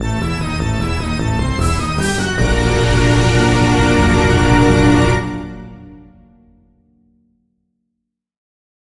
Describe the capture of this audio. Source Ripped